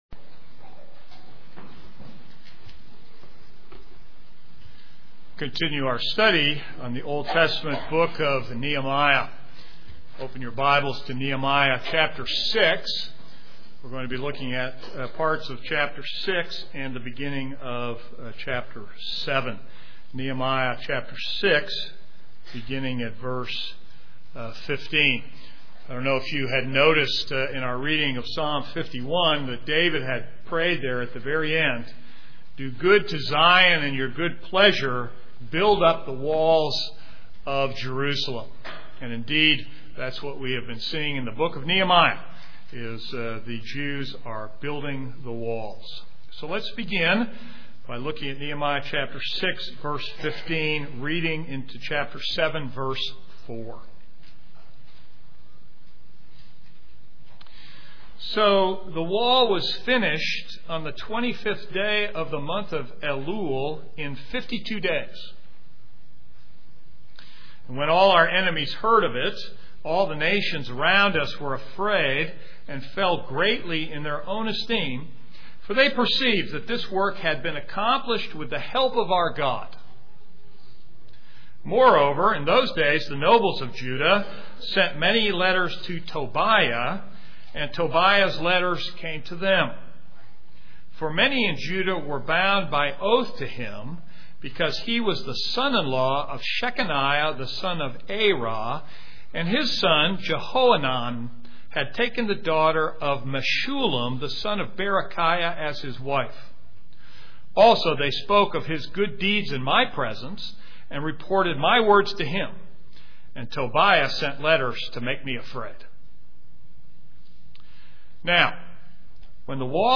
This is a sermon on Nehemiah 6:15-7:4.